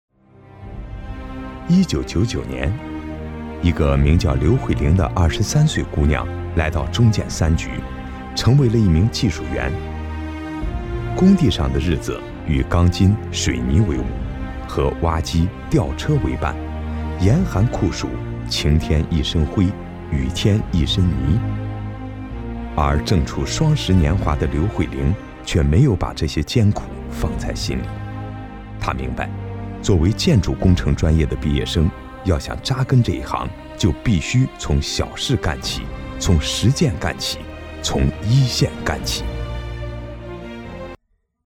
男33 人物专题 劳动楷模（稳重）.mp3